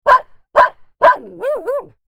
Dog Barking
Dog_barking.mp3